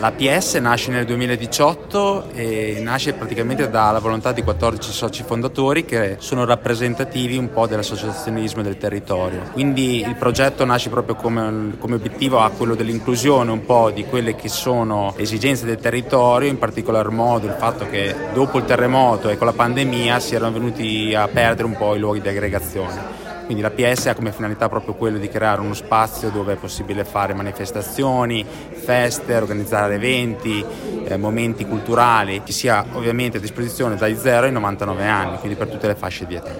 Al nostro microfono